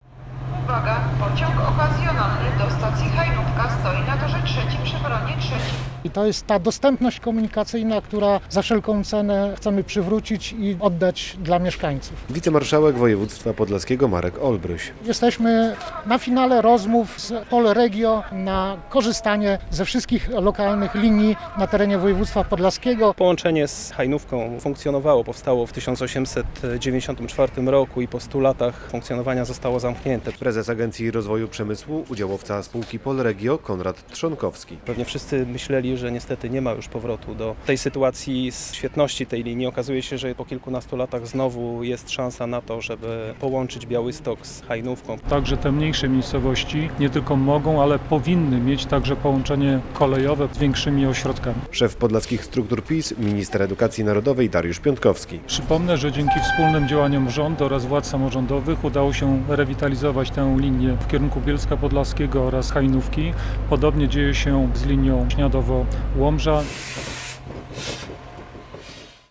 Już wkrótce wróci do rozkładu jazdy połączenie kolejowe Białystok - Hajnówka. Zapowiedzieli to na konferencji na dworcu PKP w Białymstoku politycy, samorządowcy i przedstawiciele spółki Polregio, którzy następnie wybrali się w symboliczny przejazd nową trasą.
relacja